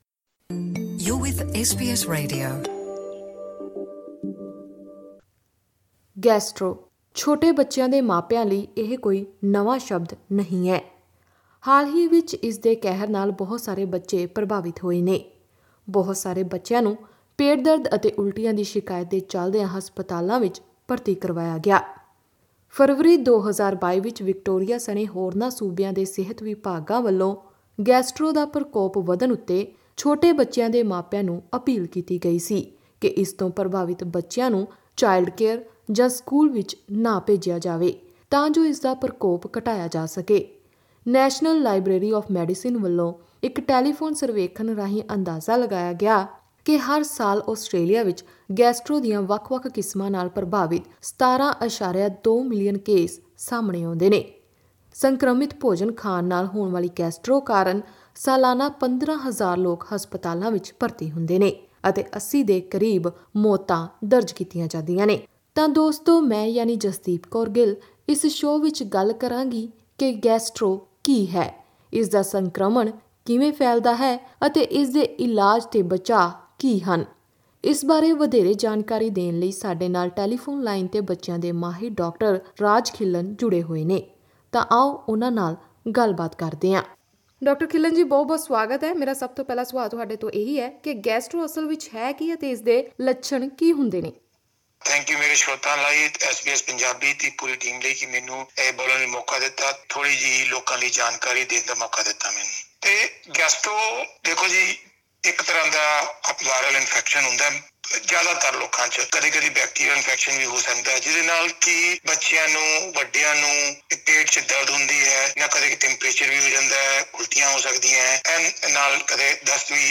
Click on the audio below to listen to the full interview in Punjabi.